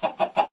mob / chicken3